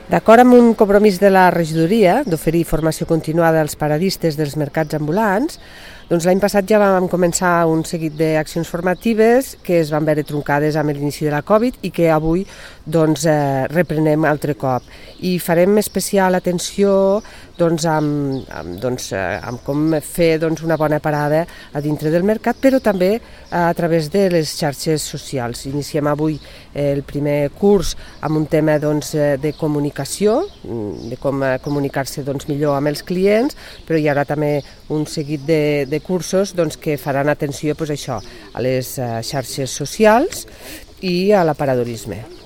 tall-de-veu-de-la-regidora-marta-gispert-sobre-el-curs-de-formacio-a-paradistes-dels-mercadets